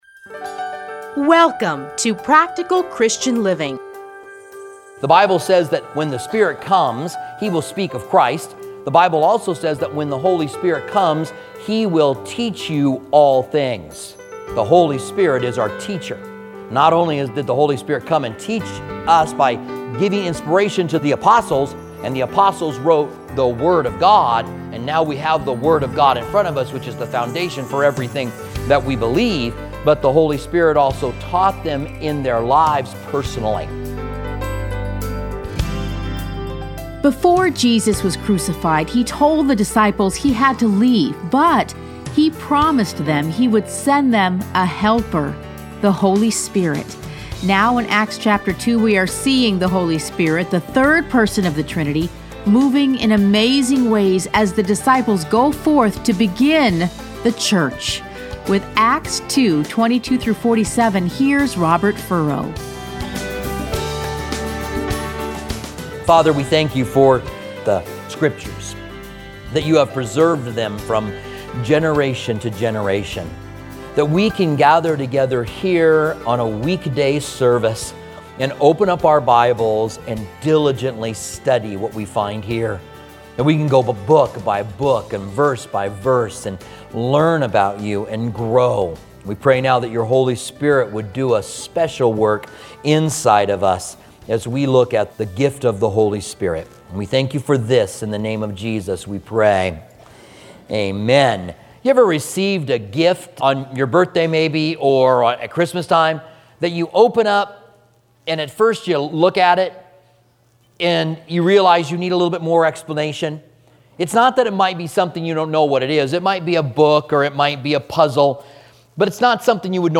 A Spirit Inspired Sermon - 2 - Part 1
Listen to a teaching from Acts 2:22-47.